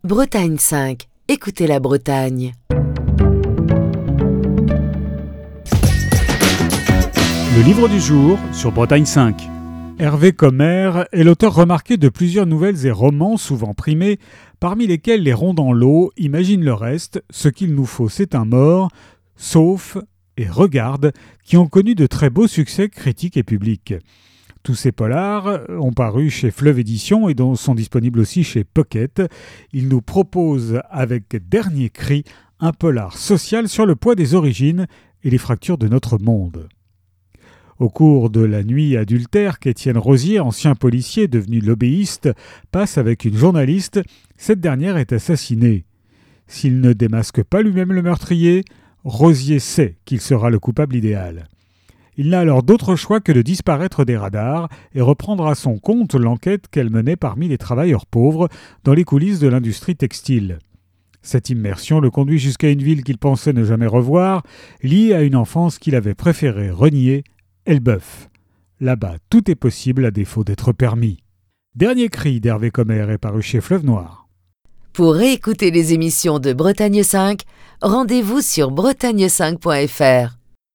Chronique du 6 mars 2025.